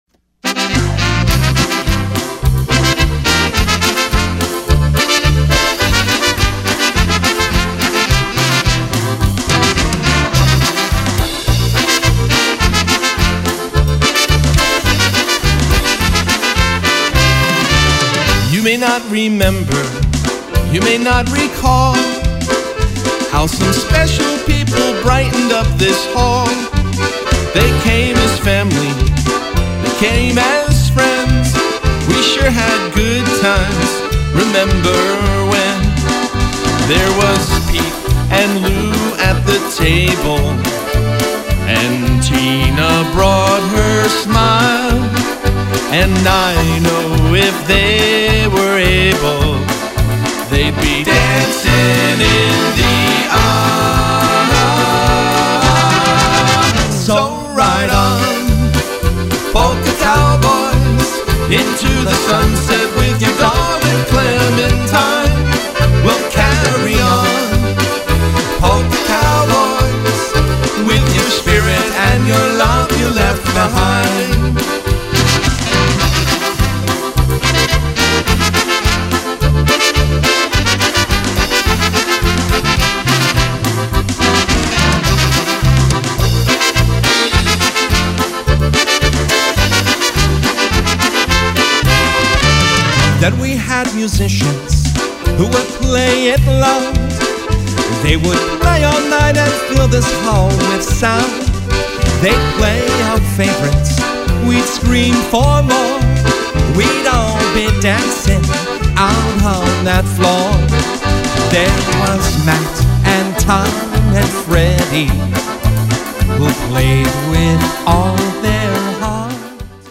features 20 musicians from over 12 favorite Polka bands.